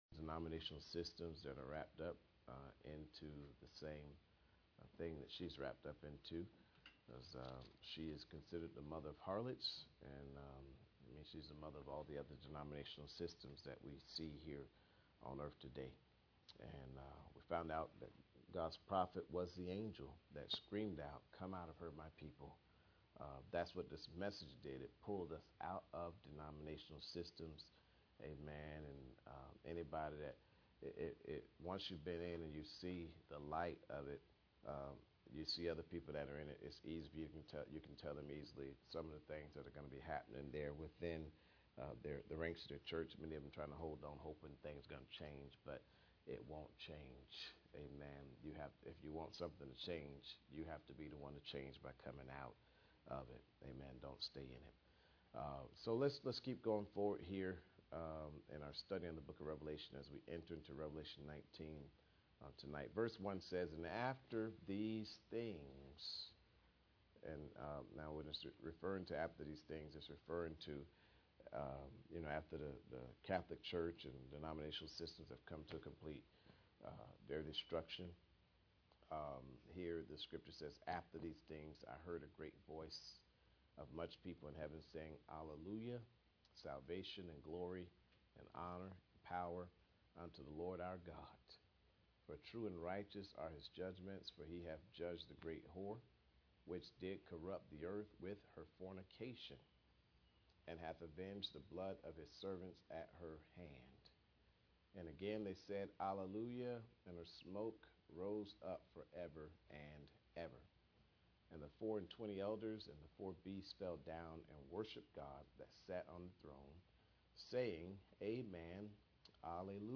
Revelations Passage: Revelation 19:1-21 Service Type: Midweek Meeting %todo_render% « Effects of The Vision Banished